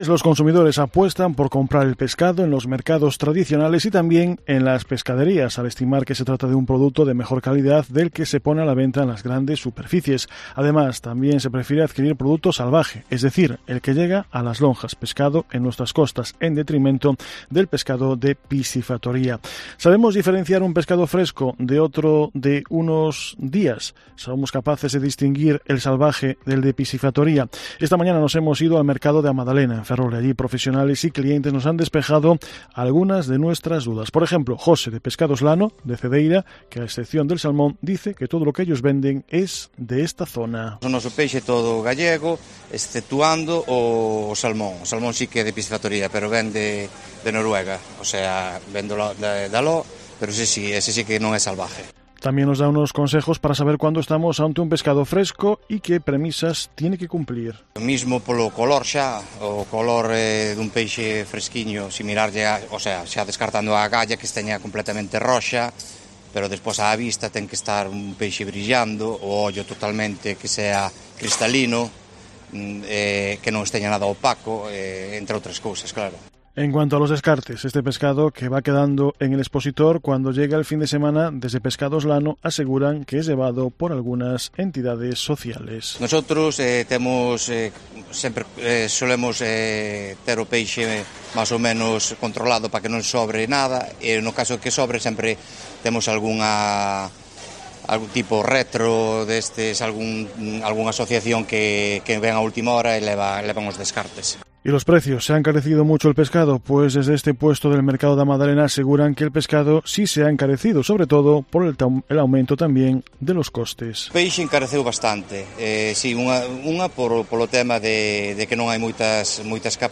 Vendedores y clientes este martes en el Mercado de A Magdalena de Ferrol